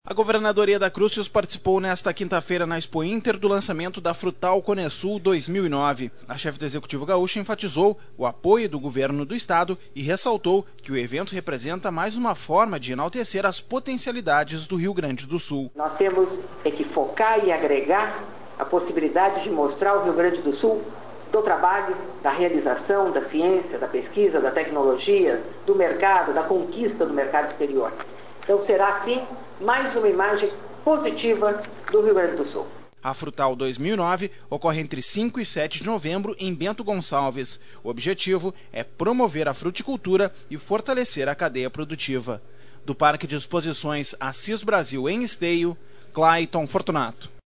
No auditório da Central de Imprensa, localizado no parque de Exposições Assis Brasil, em Esteio, a governadora Yeda Crusius participou nesta quinta-feira (3) do lançamento da Frutal Conesul.